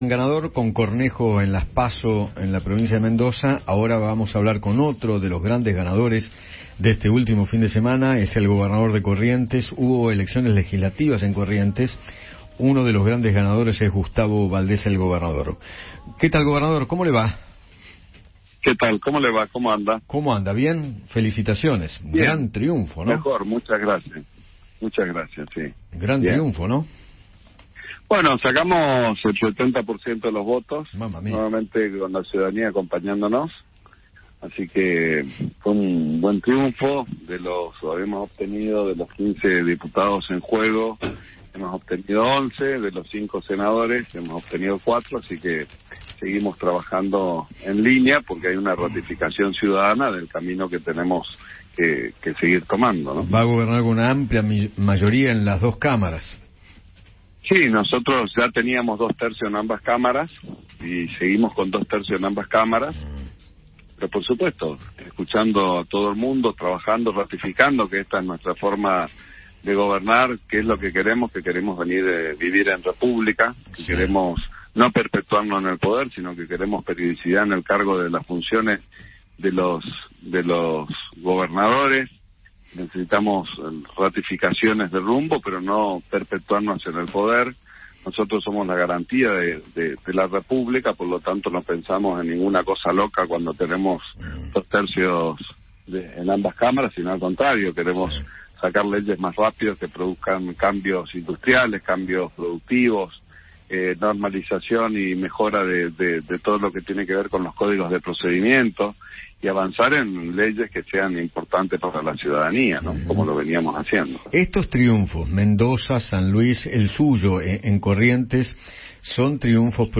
Gustavo Valdés, Gobernador de Corrientes, conversó con Eduardo Feinmann sobre la victoria del oficialismo en aquella provincia.